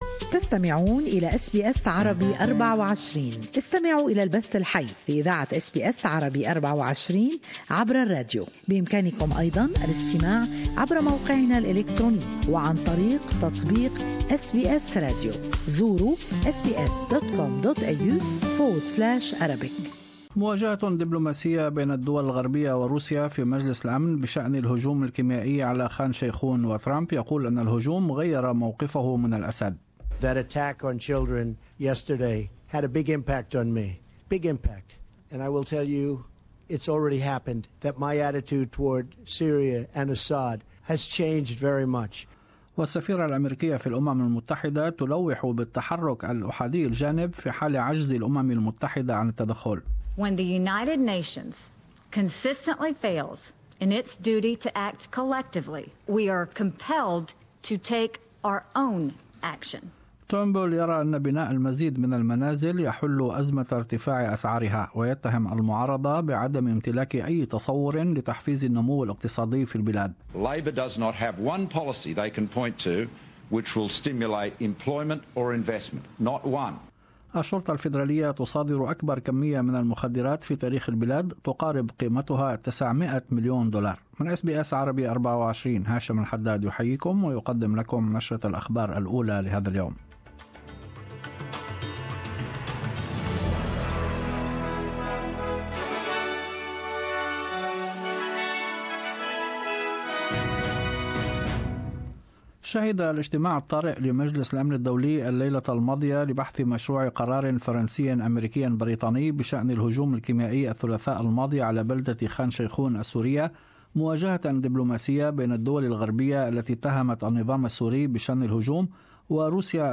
Latest Australian and world news in the morning news bulletin.